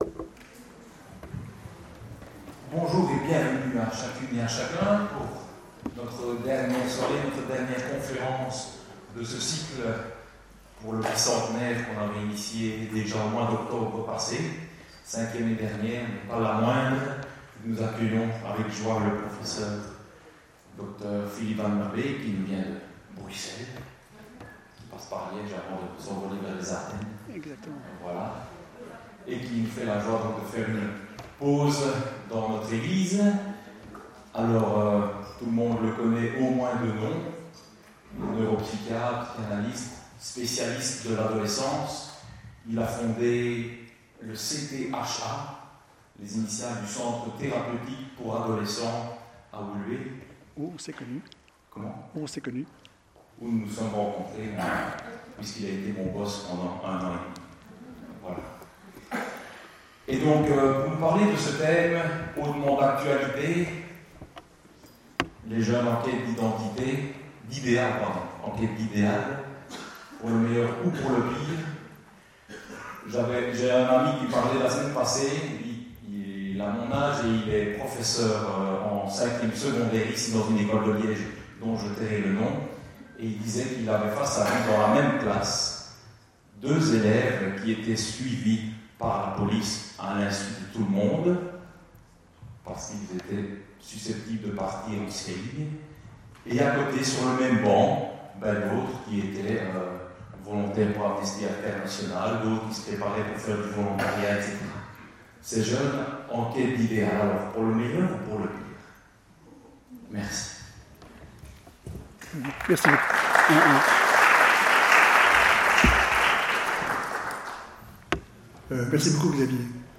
Pas évident la soirée du 7 mai à St François ! Un exposé très fouillé (et parfois fouillis!) dans son contenu – il fallait pouvoir le suivre dans ses pérégrinations dans l’espace et le temps – et un débit de paroles fort rapide qui ne rendait pas toujours les choses très audibles…